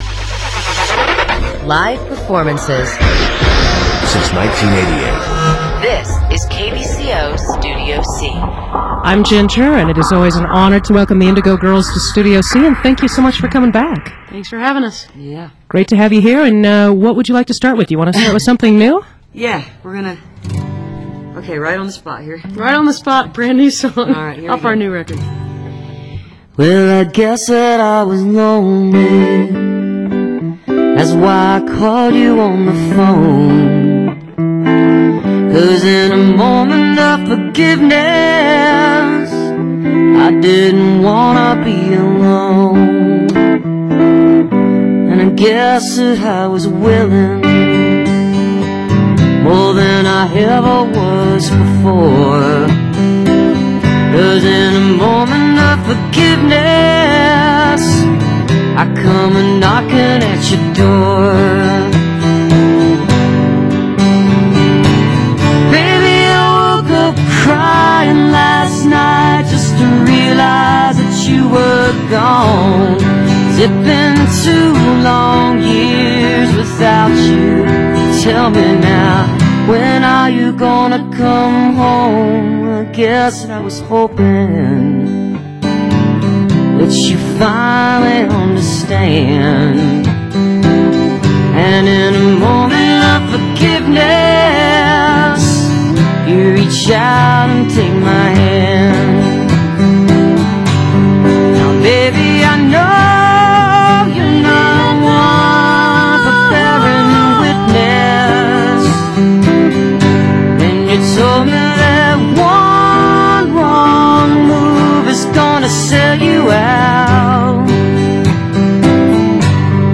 (acoustic duo performance)